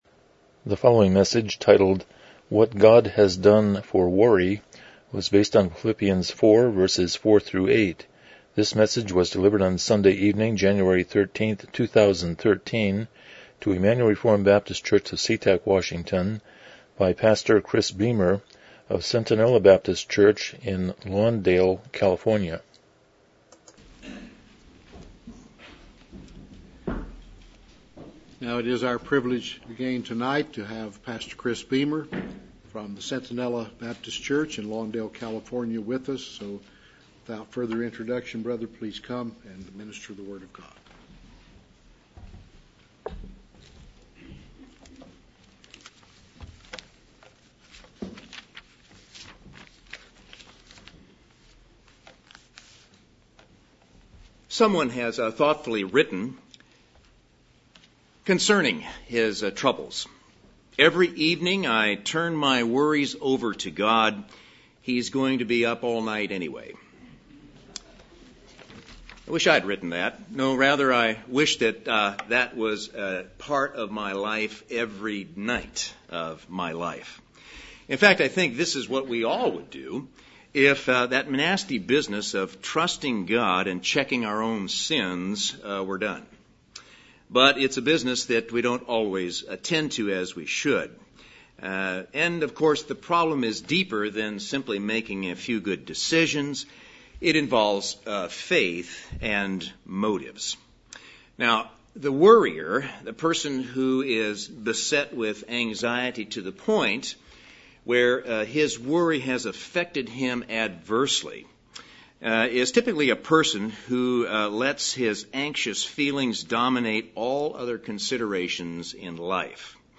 Philippians 4:4-8 Service Type: Evening Worship « When Worry Becomes Sin 22 The Sermon on the Mount